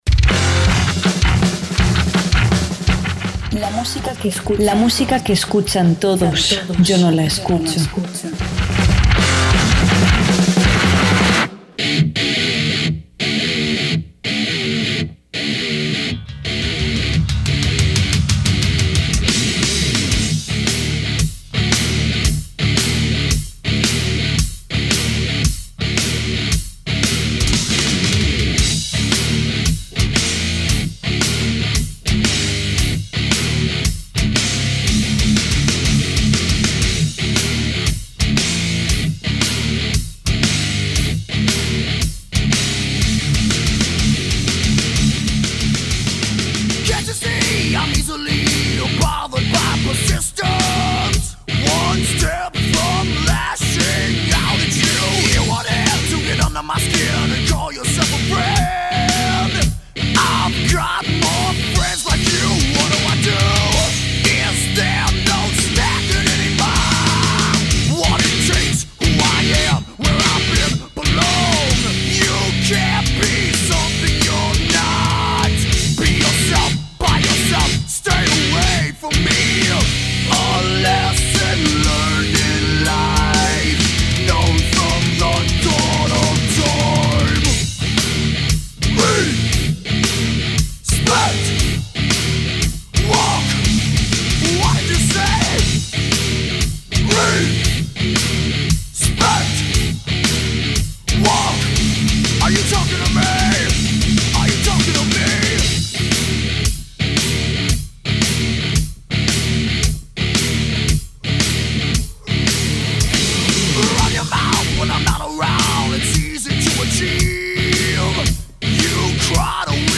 metal sinfónico
una versión en vivo
una clásica balada